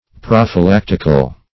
Search Result for " prophylactical" : The Collaborative International Dictionary of English v.0.48: Prophylactic \Proph`y*lac"tic\, Prophylactical \Proph`y*lac"tic*al\, a. [Gr.